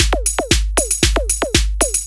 116 BPM Beat Loops Download